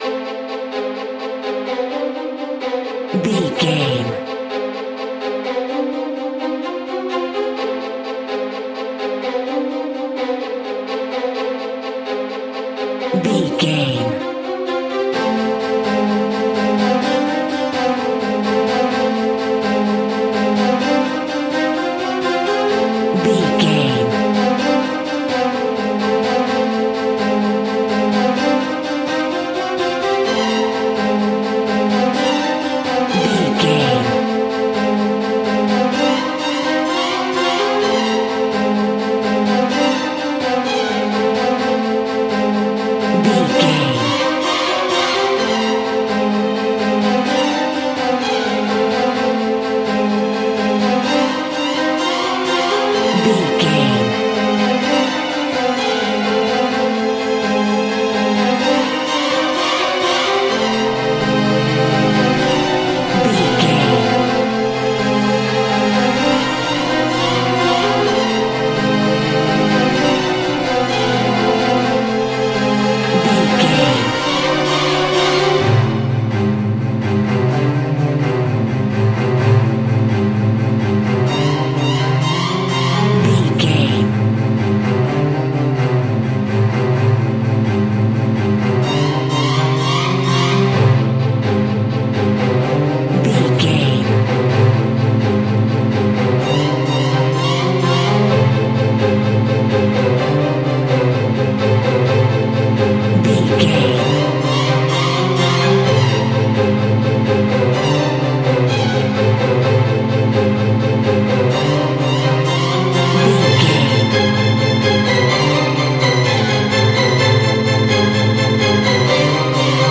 In-crescendo
Thriller
Aeolian/Minor
tension
ominous
suspense
eerie